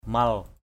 /mʌl/ aiek: mal ML —